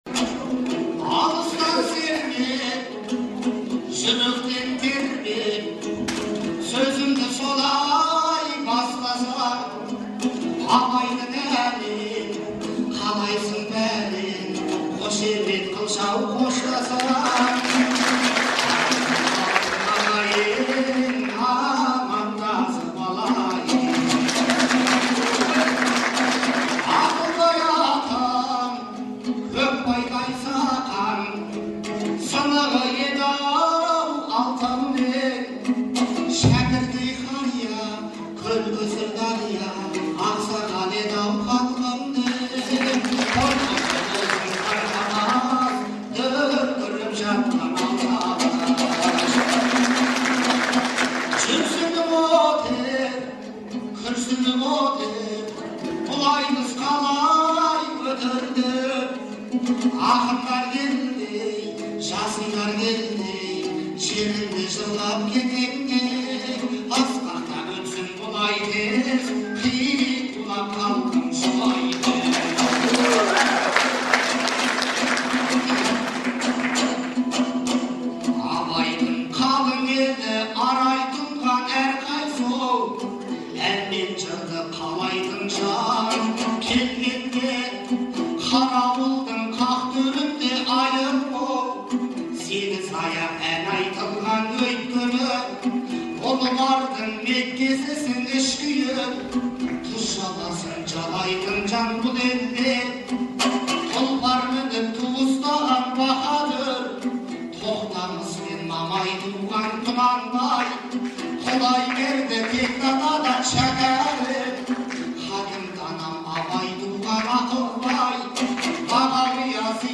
Қарауылдағы айтыс
Қыркүйектің 15-і күні Шығыс Қазақстан облысы Абай ауданының орталығы Қарауылда «Бабалар тойы – ел тойы» деген атпен Көкбай Жанатайұлы мен Ақылбайдың туғанына 150 жыл, Шәкір Әбеновтің туғанына 110 жыл толуына орай республикалық айтыс өтті.